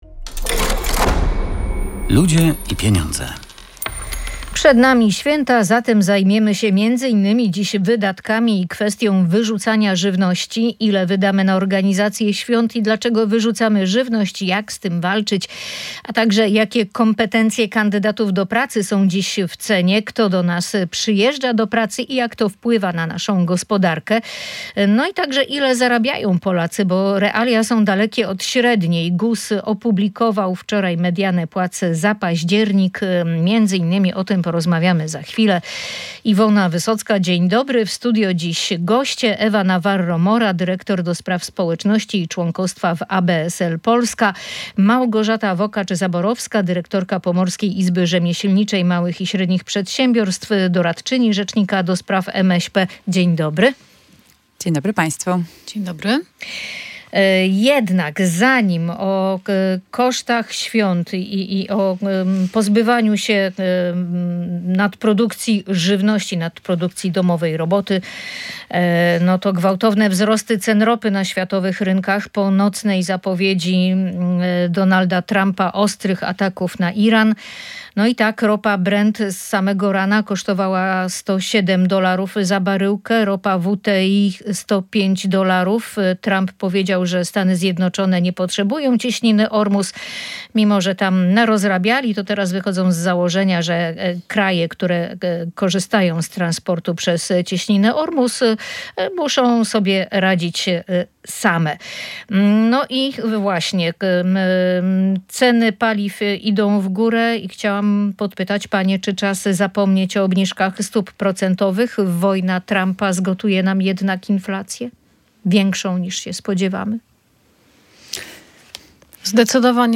Na organizację nadchodzących świąt wielkanocnych jedna osoba wyda przeciętnie 527 złotych. Co trzeci Polak przygotowuje na święta więcej jedzenia niż jest to potrzebne. Na temat wydatków świątecznych rozmawialiśmy w audycji „Ludzie i Pieniądze”.